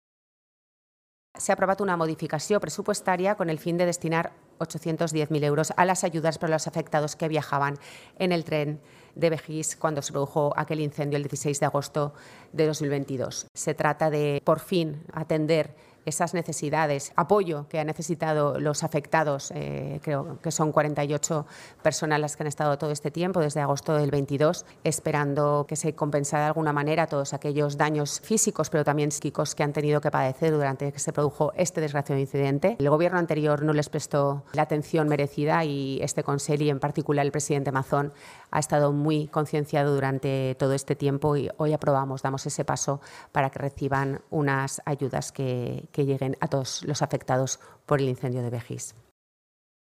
El titular de Medio Ambiente ha comparecido, junto a la portavoz y consellera de Hacienda, Ruth Merino, en la rueda de prensa posterior al pleno del Consell para informar sobre las principales novedades de este proyecto legislativo antes de su remisión al Consell Jurídic Consultiu y al Consejo Económico y Social como paso previo a su aprobación final por el Consell y Les Corts.